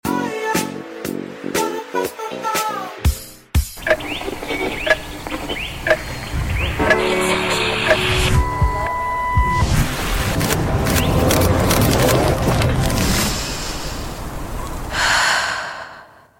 What would your favorite edit sound effects free download By motionarray 0 Downloads 15 months ago 16 seconds motionarray Sound Effects About What would your favorite edit Mp3 Sound Effect What would your favorite edit sound like with only SFX?